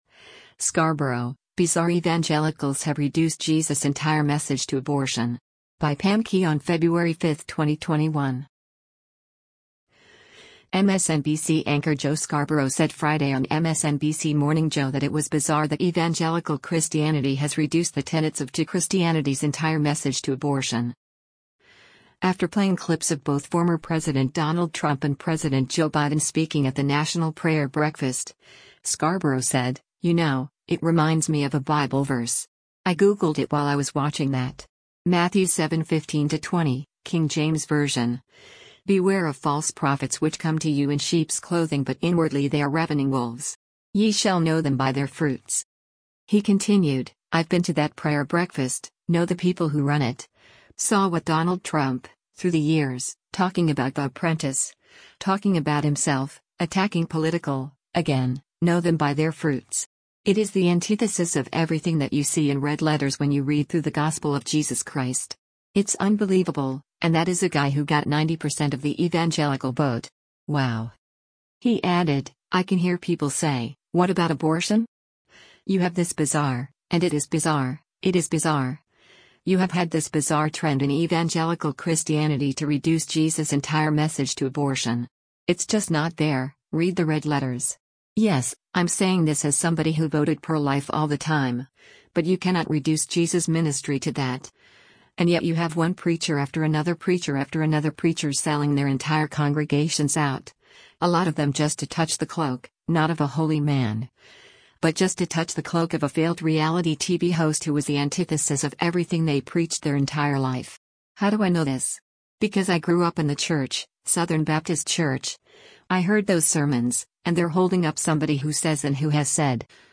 MSNBC anchor Joe Scarborough said Friday on MSNBC “Morning Joe” that it was “bizarre” that Evangelical Christianity has reduced the tenets of to Christianity’s “entire message to abortion.”